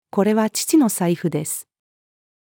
これは父の財布です。-female.mp3